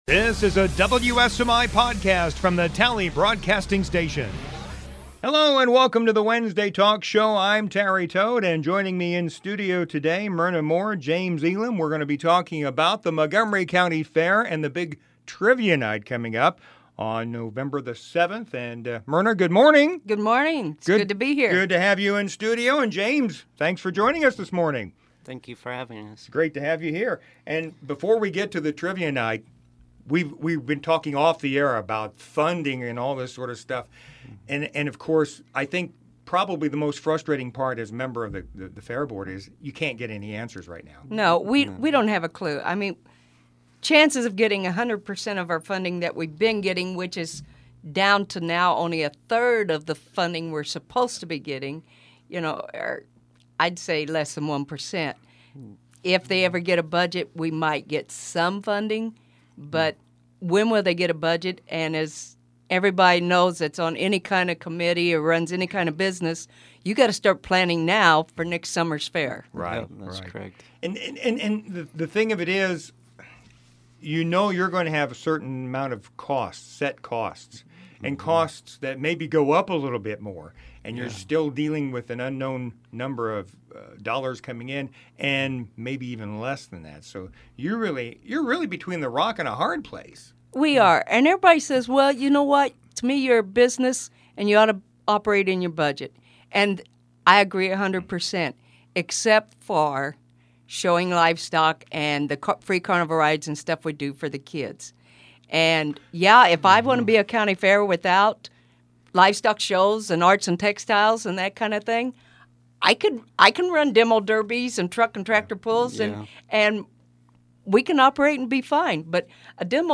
Wednesday Talk Show "Trivia Night"